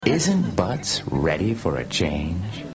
Isnt Butts ready for a change - annoucer guy